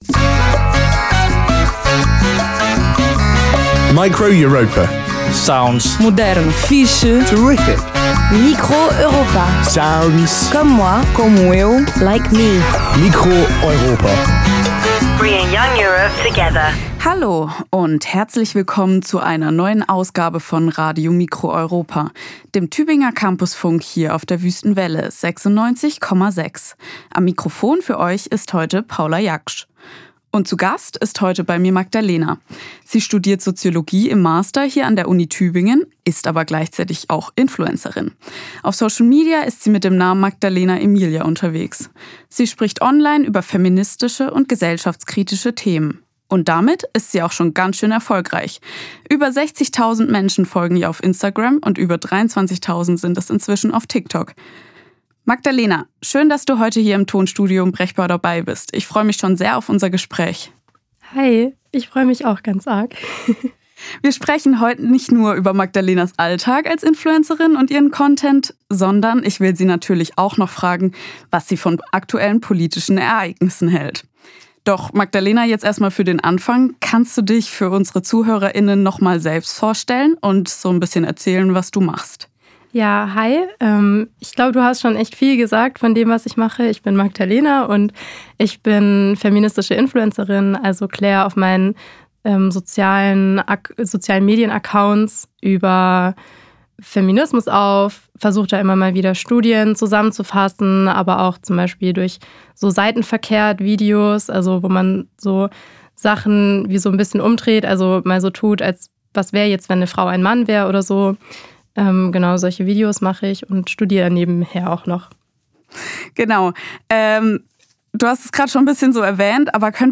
Studiogespräch
Form: Live-Aufzeichnung, geschnitten